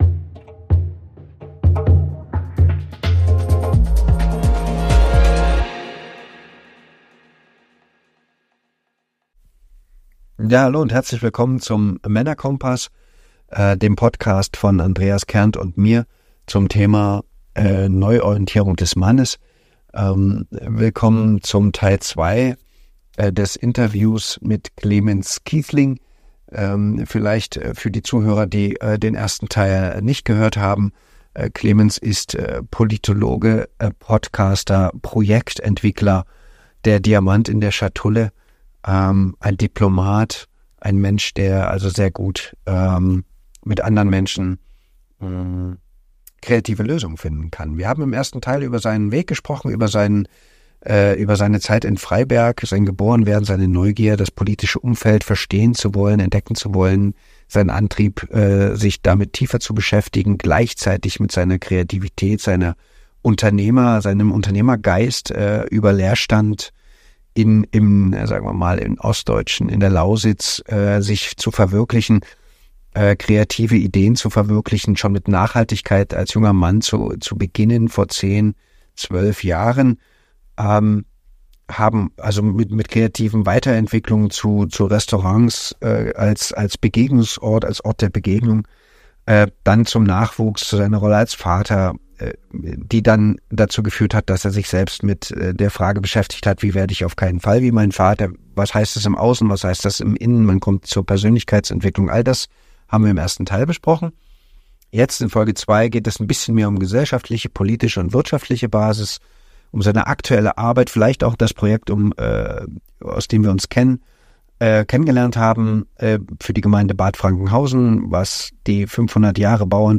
Bis gleich, Wir laden dich herzlich ein, beide Teile des Interviews zu hören und aktiv an der Diskussion teilzunehmen.